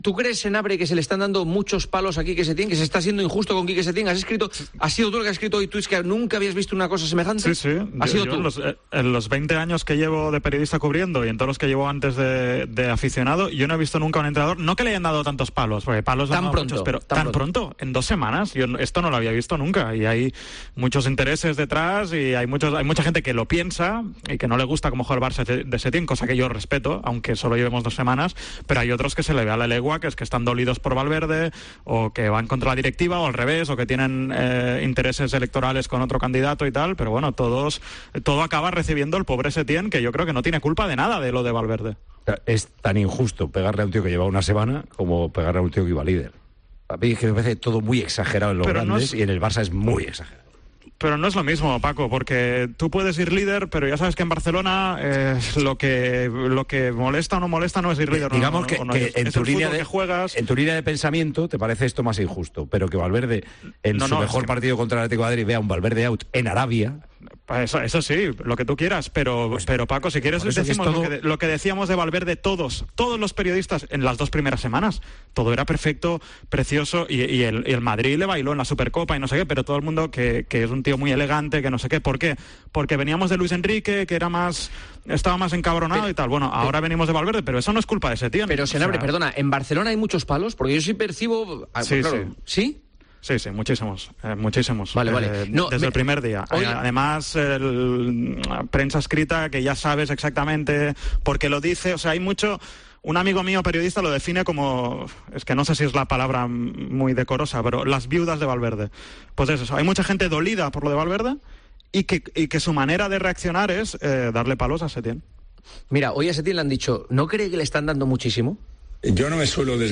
AUDIO: En El Partidazo del miércoles debaten Juanma Castaño, Dani Senabre, Paco González y Tomás Guasch